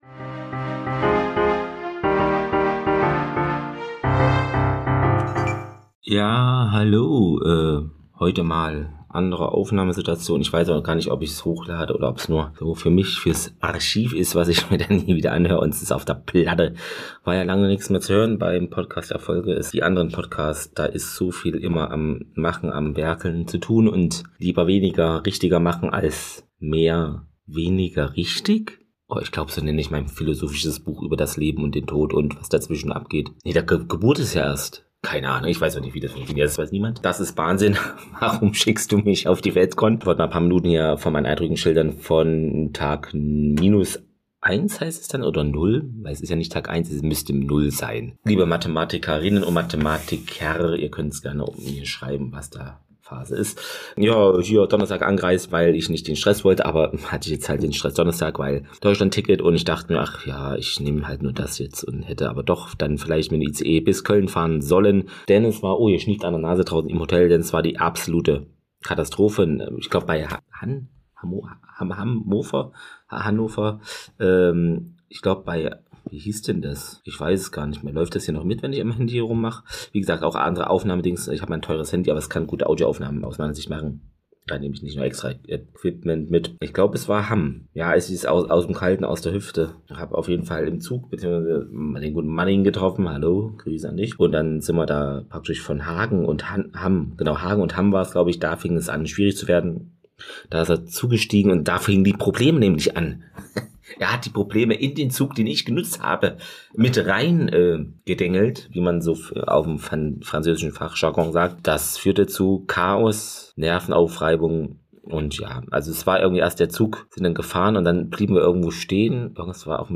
Nerdige Gespräche mit tollen Gästen, meistens über Medienerzeugnisse, die uns aktuell beschäftigen.